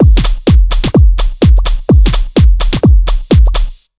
127BEAT6 8-R.wav